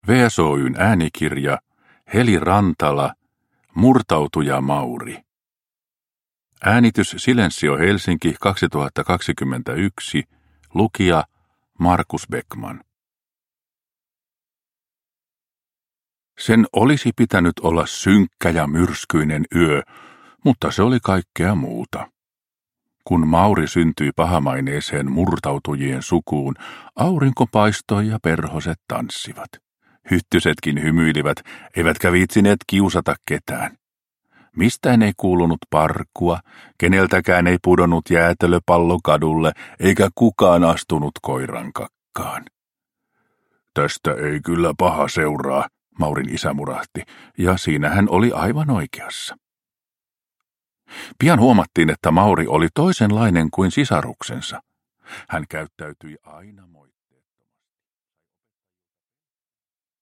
Murtautuja Mauri – Ljudbok – Laddas ner